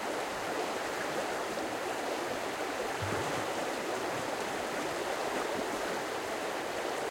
river.ogg